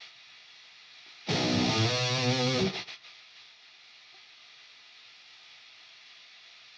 I am getting a constant hum in my recordings which I can hear through both my headphones and speakers, as well as in the actual recordings - see attached example of the hum with a random distorted guitar note as reference.
The hum is amplified (as per the recording attached) when I use an amp sim with distortion.